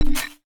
UIClick_Next Button 02.wav